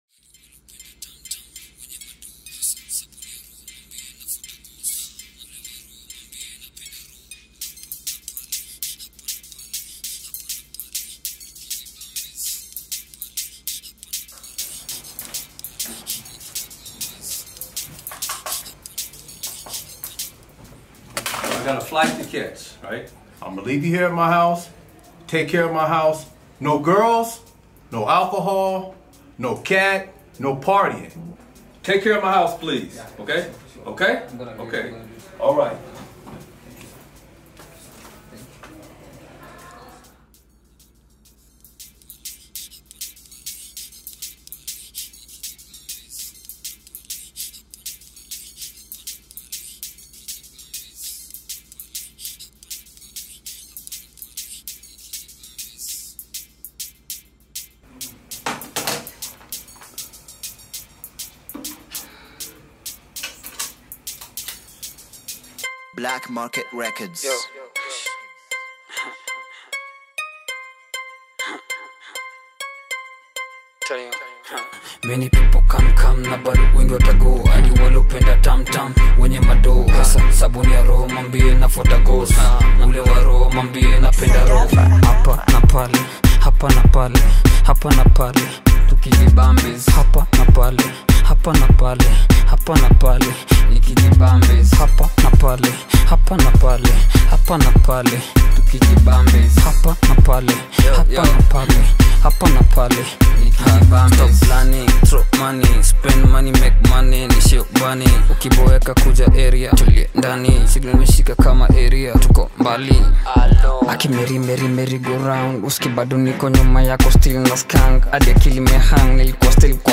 Kenyan hip hop